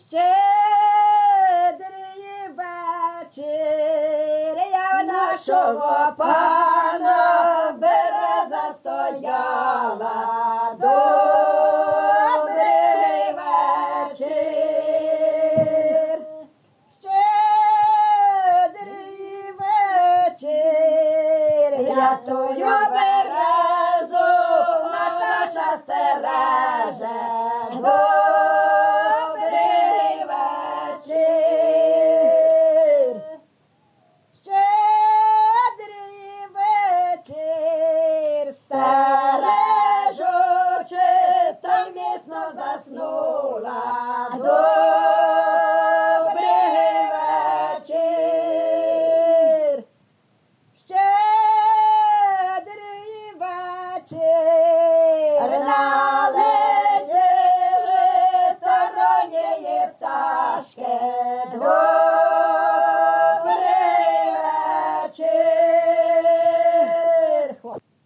ЖанрЩедрівки
Місце записус. Куземин, Охтирський район, Сумська обл., Україна, Слобожанщина